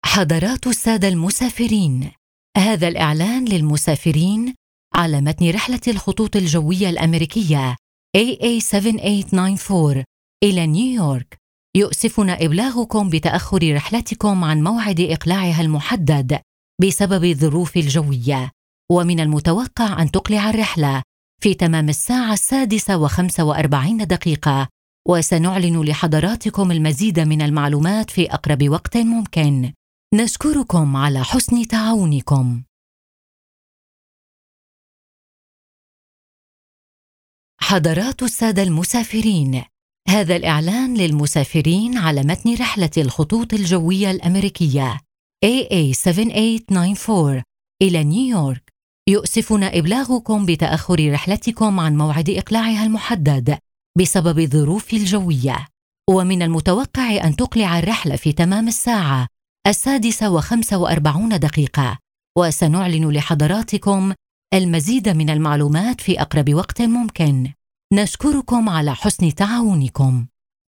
AR RA EL 01 eLearning/Training Female Arabic